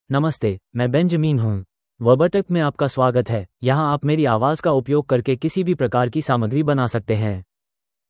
Benjamin — Male Hindi (India) AI Voice | TTS, Voice Cloning & Video | Verbatik AI
Voice sample
Listen to Benjamin's male Hindi voice.
Male
Benjamin delivers clear pronunciation with authentic India Hindi intonation, making your content sound professionally produced.